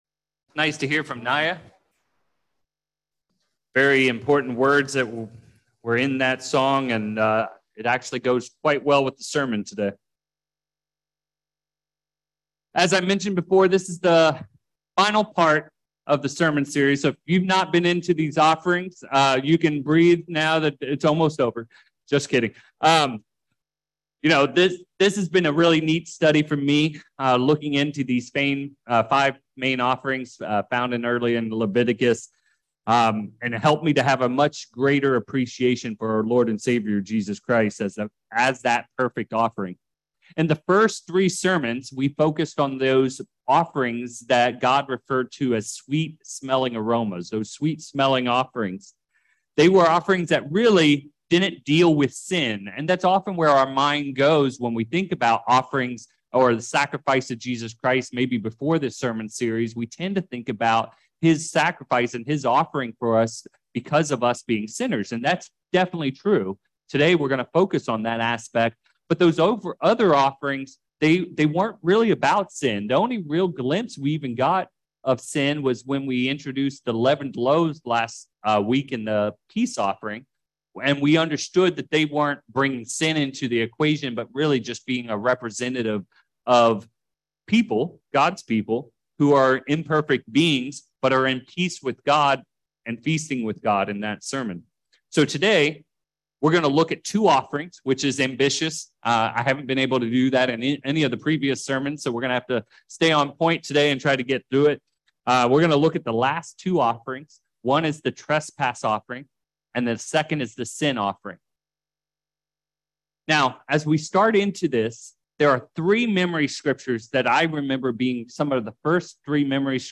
4/2/23 This is the fourth and final sermon in a series looking at the five main offerings found in the early chapters of Leviticus. This sermon dives into the difference between trespass and sin and looks at some of the deeper meanings contained in these offerings for us Christians.